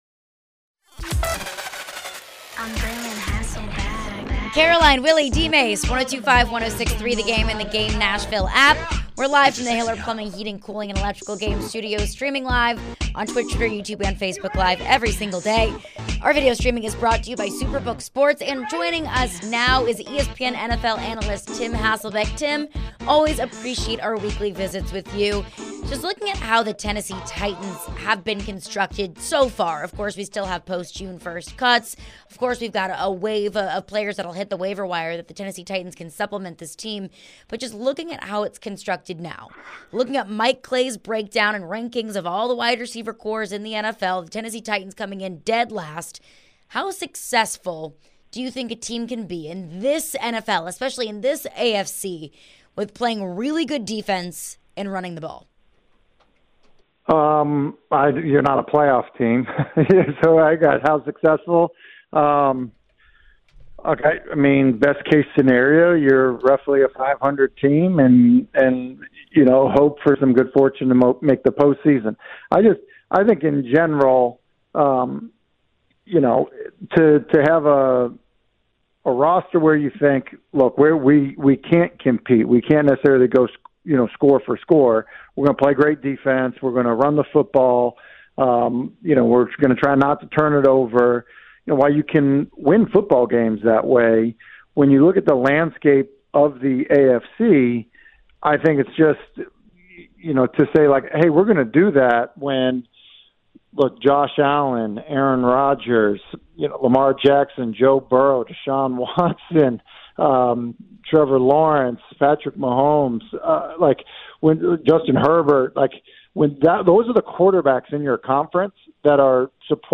ESPN NFL Analyst Tim Hasselbeck joins to discuss college quarterback development and more.